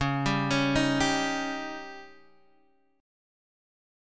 C#sus2b5 chord